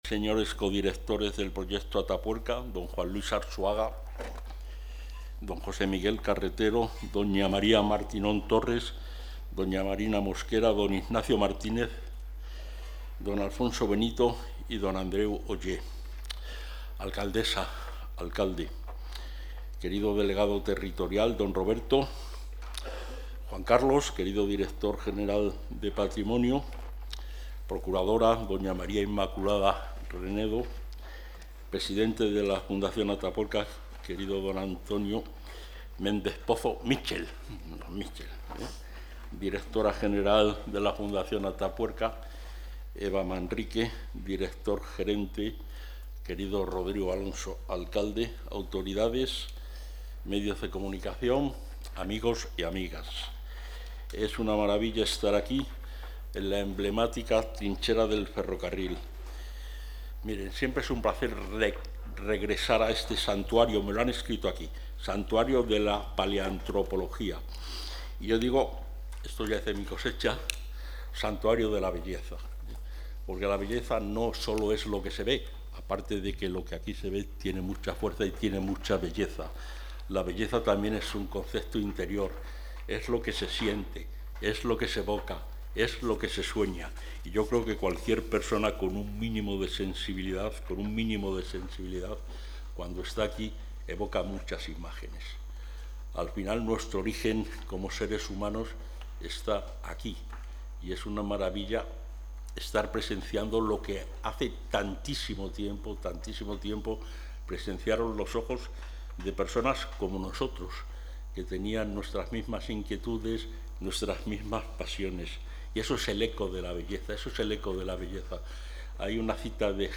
El consejero de Cultura, Turismo y Deporte, Gonzalo Santonja, ha participado hoy en la presentación del balance de la campaña de...
Intervención del consejero.